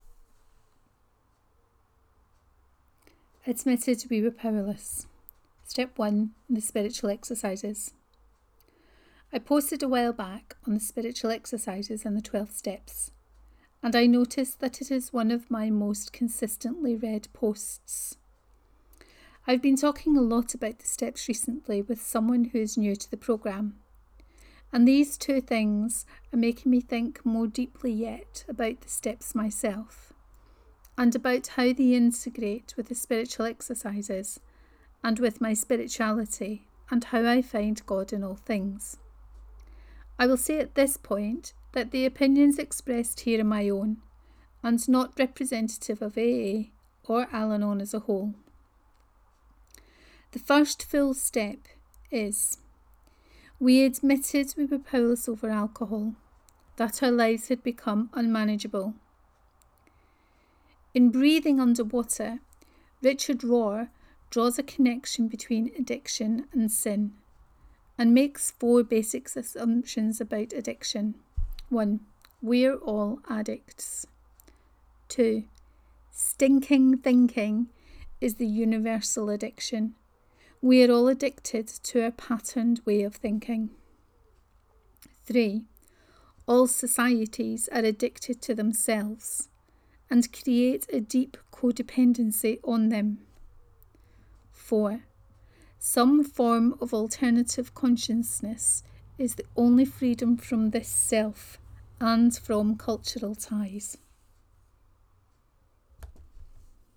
Step 1 and The Spiritual Exercises 1: Reading of this post